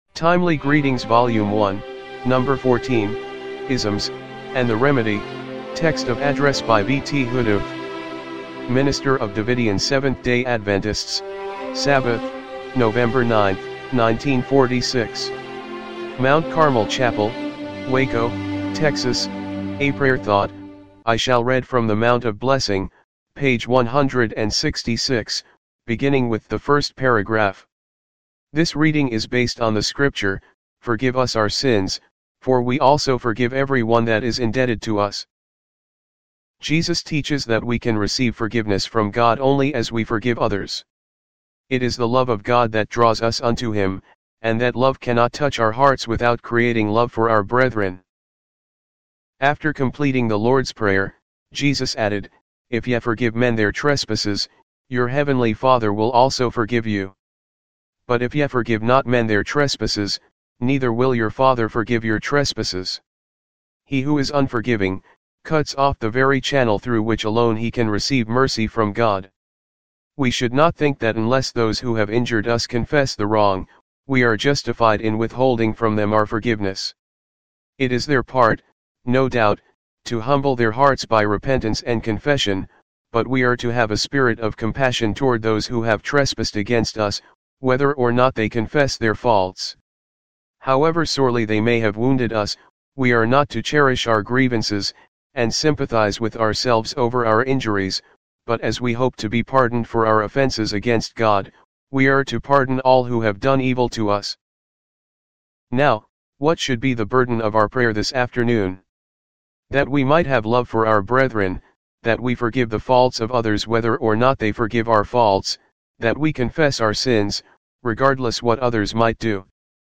timely-greetings-volume-1-no.-14-mono-mp3.mp3